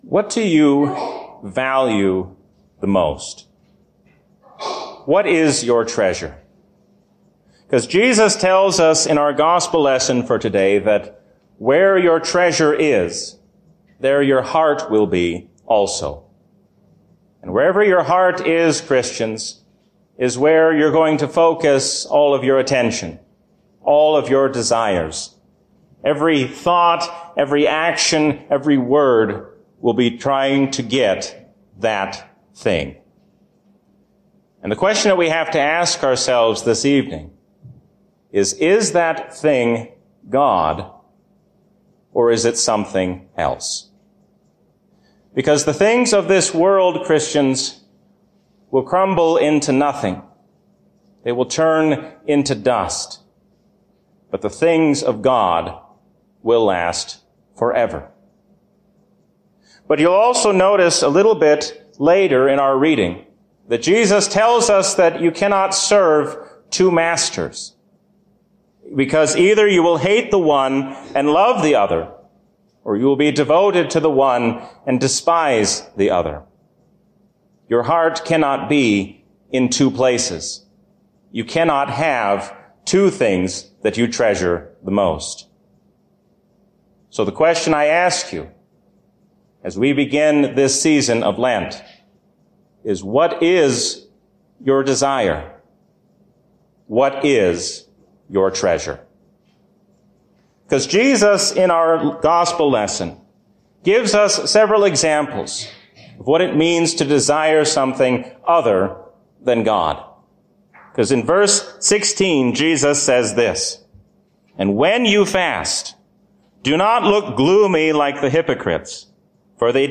A sermon from the season "Trinity 2024." Let us seek to resolve our disputes in true unity and peace, because God has made us one in Jesus Christ.